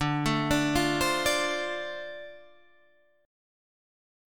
Dm7 Chord
Listen to Dm7 strummed